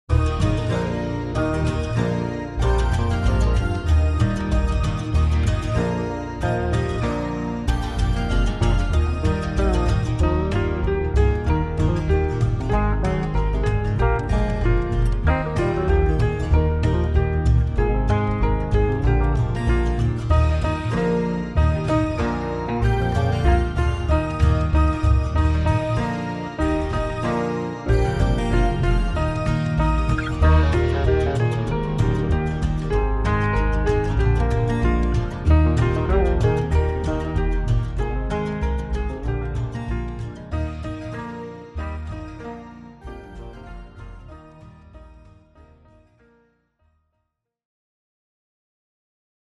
Latviešu tautas dziesma Play-along.
Spied šeit, lai paklausītos Demo ar melodiju